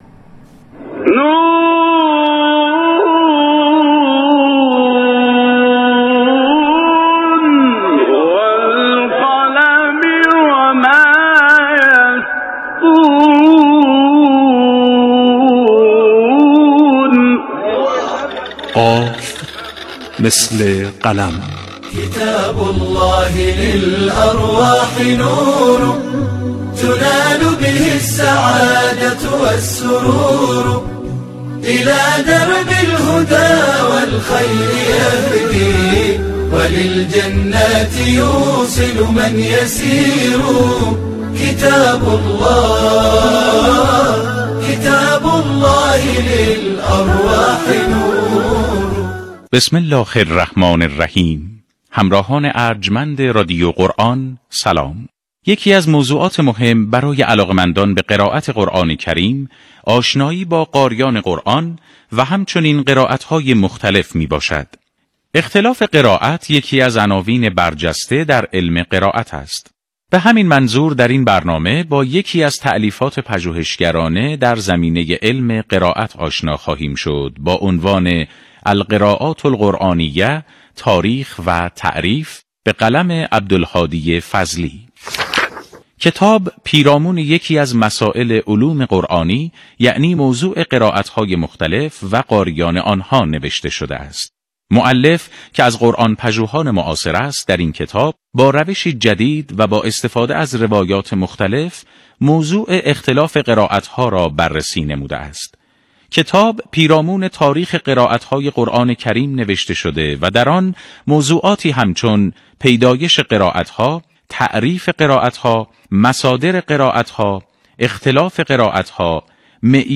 این برنامه که به صورت گفتارمحور و از سوی گروه آموزش رادیو قرآن تهیه می‌شود، طی پنج دقیقه به معرفی مشخصات هر یک از کتاب‌های مورد وثوق در حوزه‌های حفظ و تلاوت قرآن می‌پردازد و در آن مخاطب را با بخش‌هایی از کتاب و مشخصات نویسنده و بیان زمینه‌های کاربرد کتاب آشنا می‌کند.